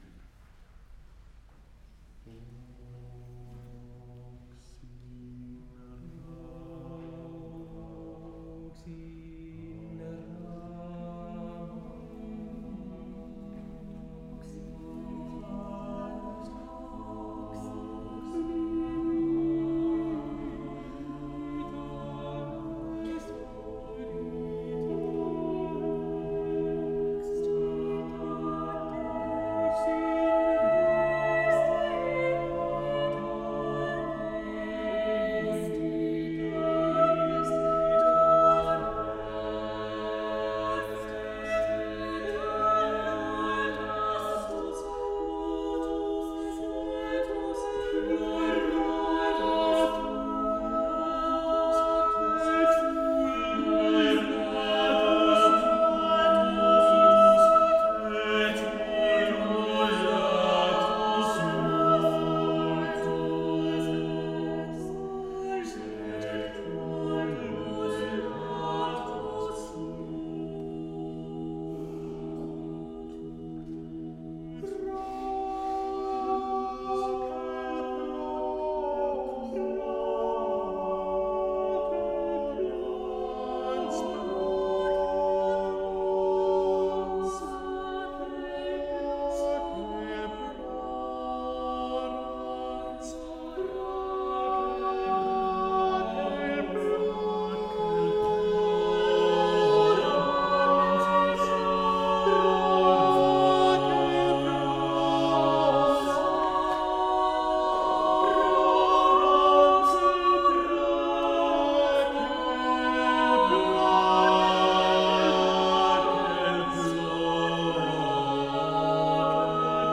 The Story of Bethlehem - Vienne - enregistrement radio
Notre concert de Noël à Vienne le 18 décembre - 'The Story of Bethlehem' - a été enregistré pour la radio par ORF.
Si vous cliquez ici , vous pouvez écouter ce morceau de l'enregistrement : Vox in Rama ~ motet à 5 : Giaches de Wert (1535-1596)